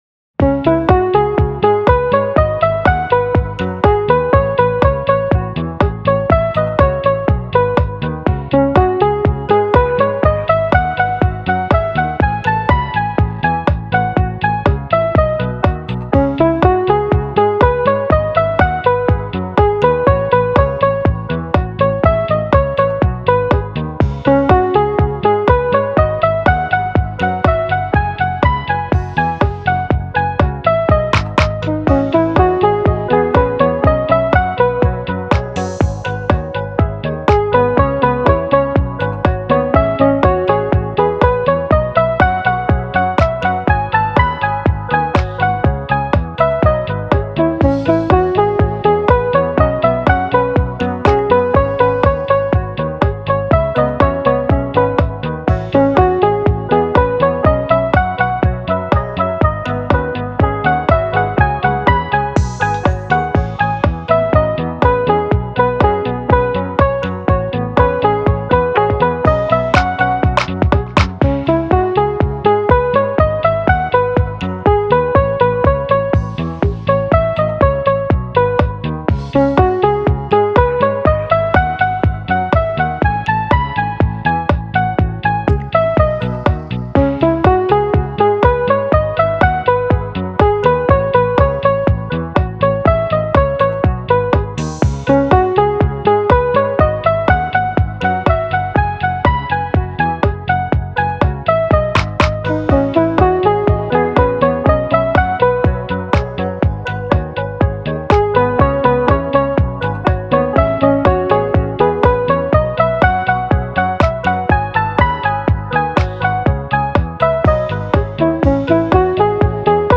オルタナティヴ フリーBGM